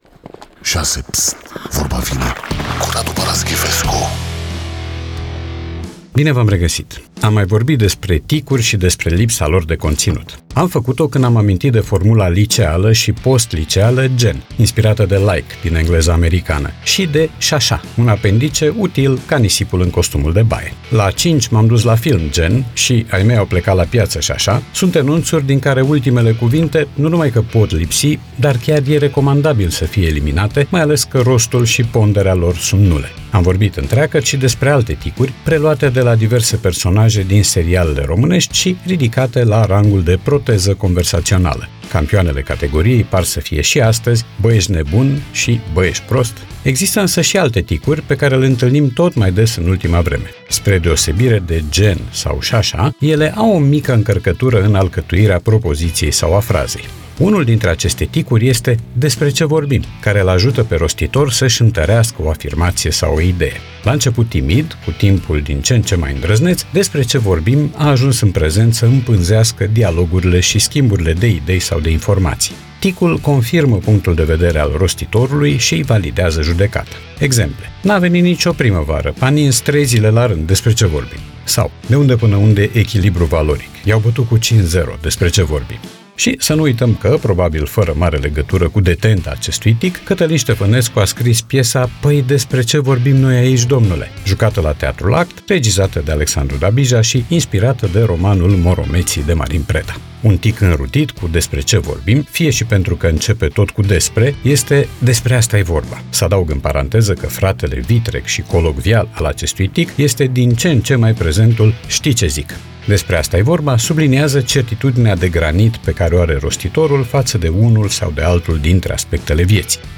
Podcast 1 aprilie 2025 Vezi podcast Vorba vine, cu Radu Paraschivescu Radu Paraschivescu iti prezinta "Vorba vine", la Rock FM.